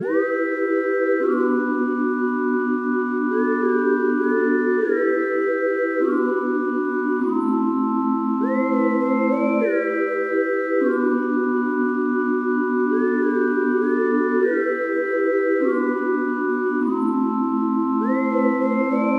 空中口哨合成器1
描述：E小调空灵的口哨合成器。
标签： 100 bpm Trap Loops Synth Loops 3.23 MB wav Key : Em Ableton Live
声道立体声